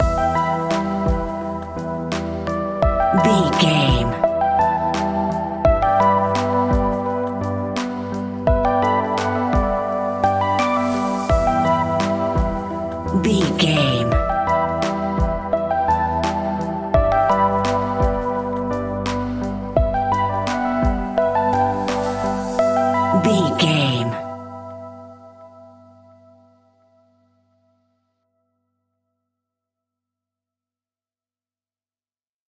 Aeolian/Minor
hip hop music
hip hop instrumentals
chilled
laid back
hip hop drums
hip hop synths
piano
hip hop pads